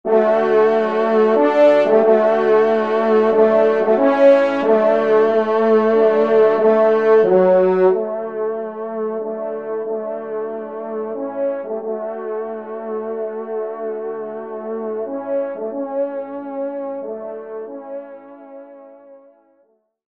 Genre : Fantaisie Liturgique pour quatre trompes
Pupitre 3° Trompe